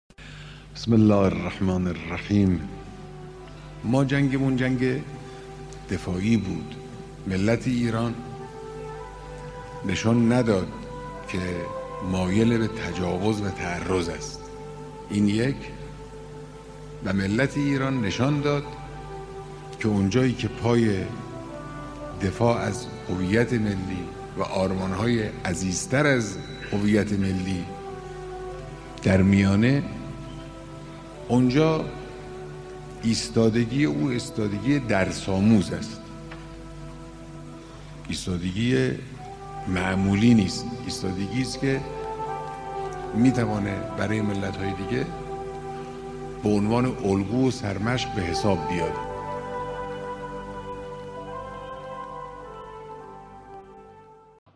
صوت بیانات رهبر انقلاب اسلامی
صوت بیانات مقام معظم رهبری